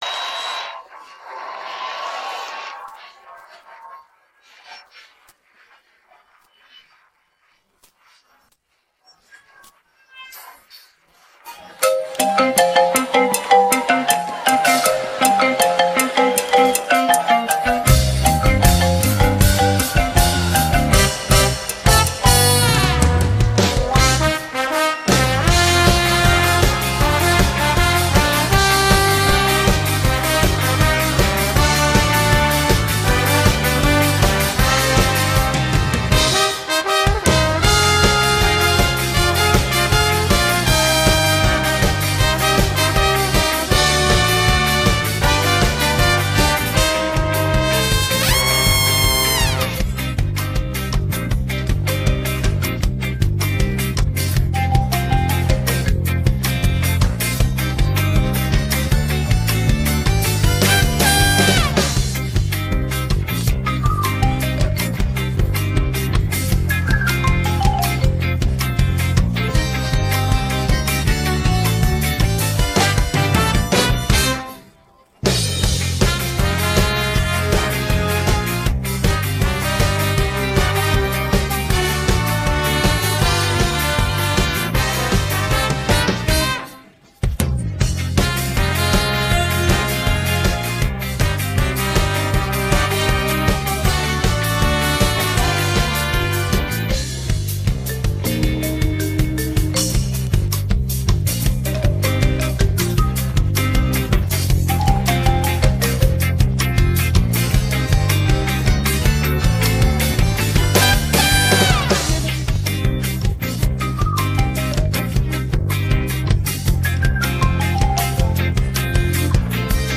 rock караоке